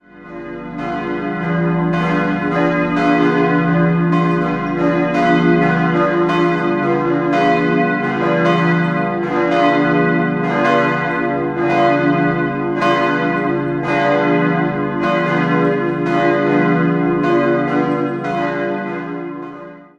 3-stimmiges Geläut: h°-d'-f' Alle Glocken wurden 1922 vom Bochumer Verein für Gussstahlfabrikation gegossen.